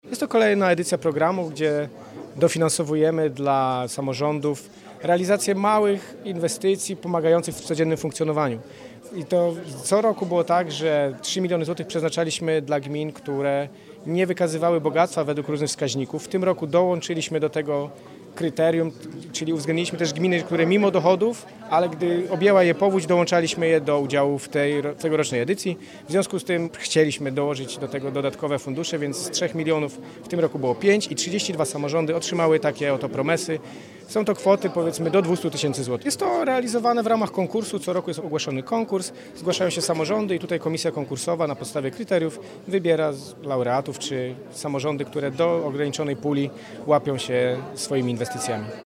– Inicjatywa ta funkcjonuje od lat i służy dofinansowaniu małych inwestycji, istotnych dla życia lokalnych społeczności – tłumaczy Michał Rado, wicemarszałek Województwa Dolnośląskiego.